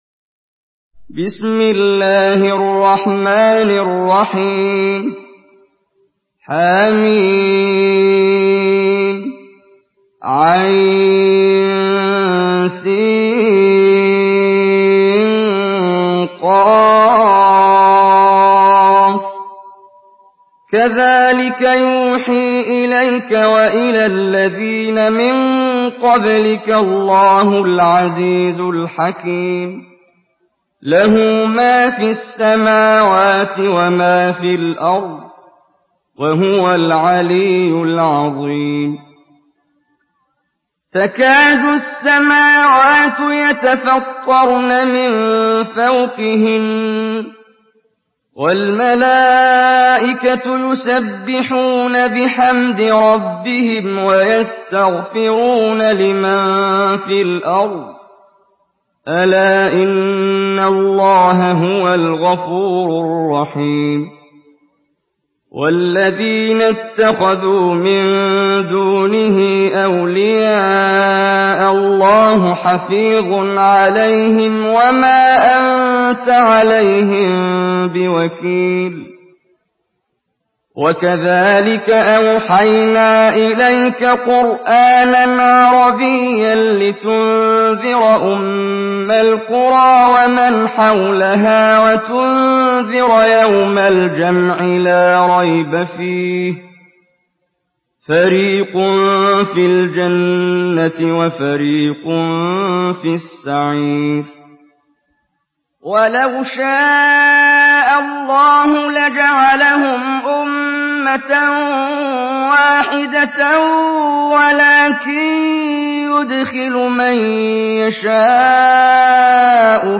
سورة الشورى MP3 بصوت محمد جبريل برواية حفص
مرتل